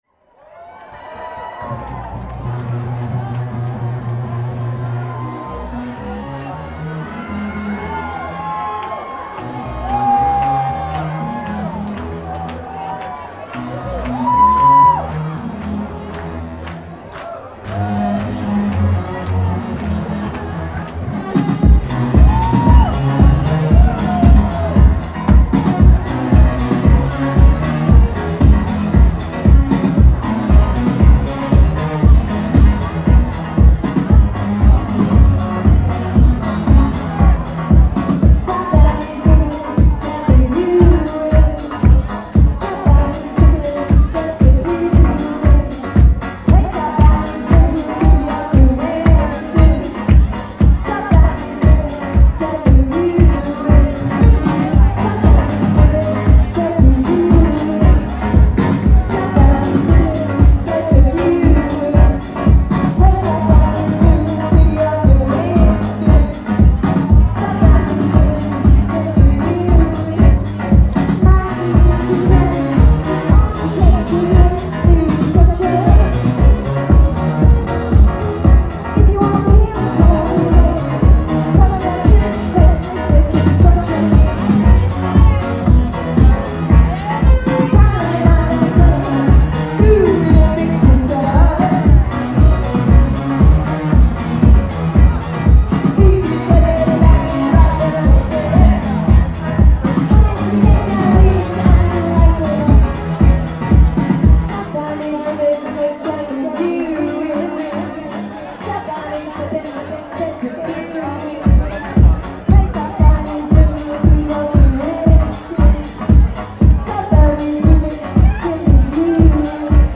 male/female duo